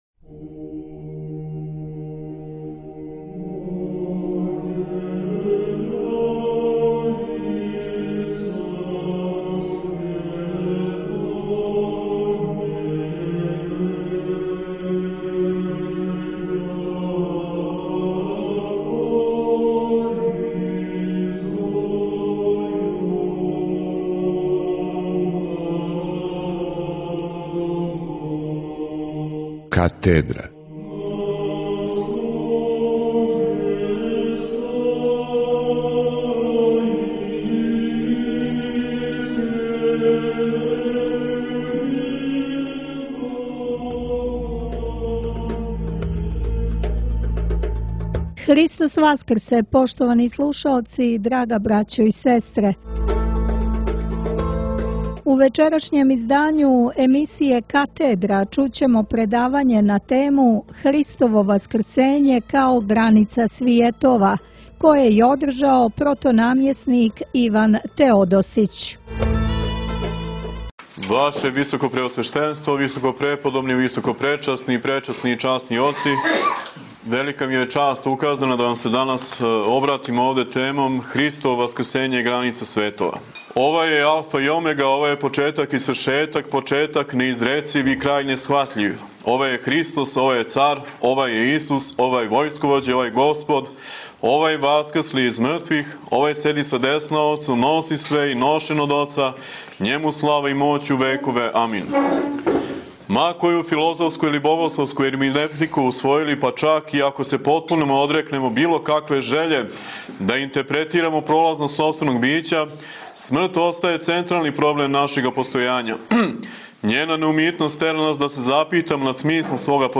Предавање је одржано на братском састанку свештенства са благословом и у присуству Његовог Високопреосвештенства Митрополита шумадијског г. Јована.